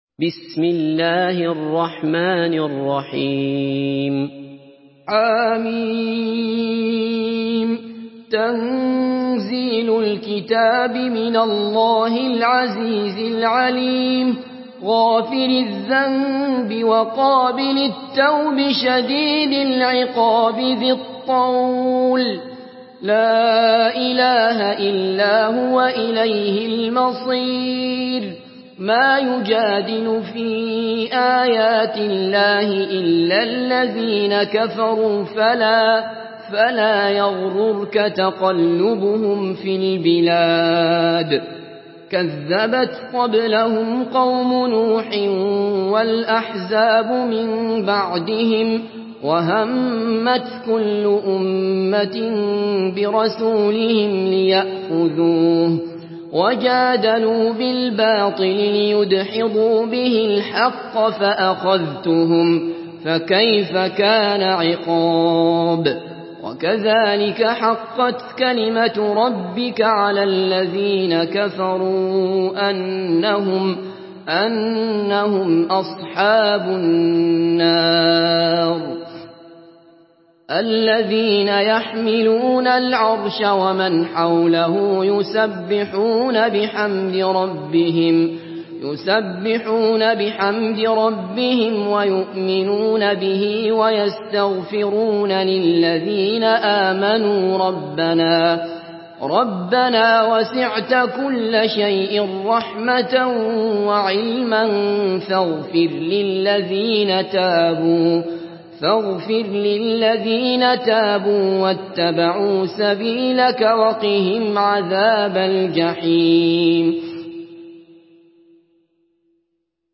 سورة غافر MP3 بصوت عبد الله بصفر برواية حفص
مرتل حفص عن عاصم